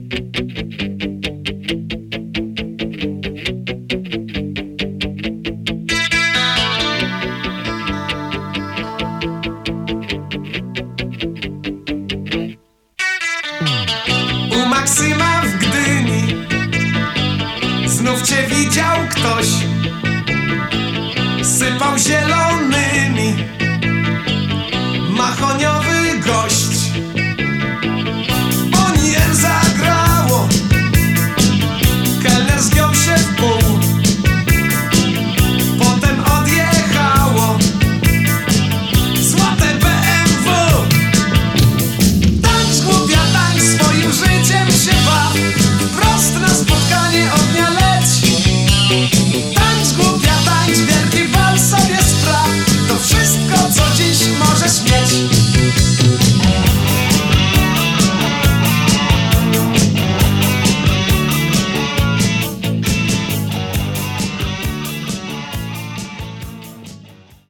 VOC GUITAR KEYB BASS DRUMS TEKST
zespół rockowy założony w 1981